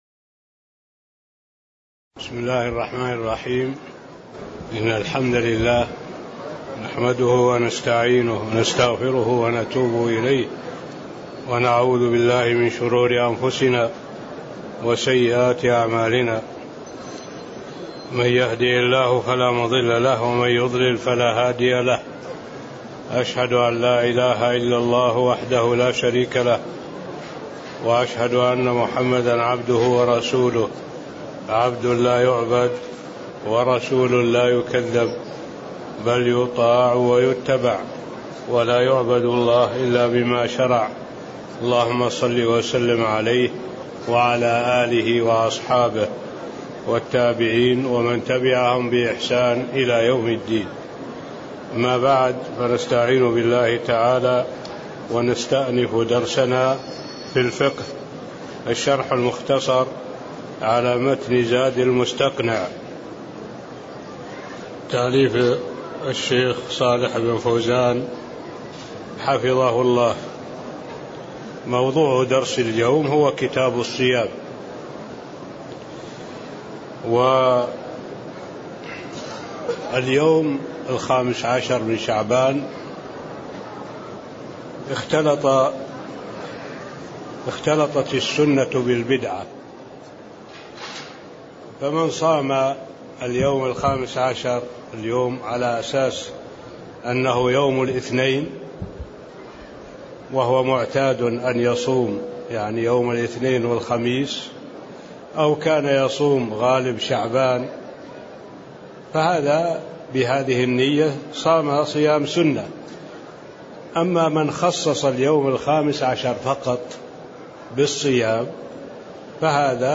تاريخ النشر ١٥ شعبان ١٤٣٤ هـ المكان: المسجد النبوي الشيخ: معالي الشيخ الدكتور صالح بن عبد الله العبود معالي الشيخ الدكتور صالح بن عبد الله العبود المقدمة (02)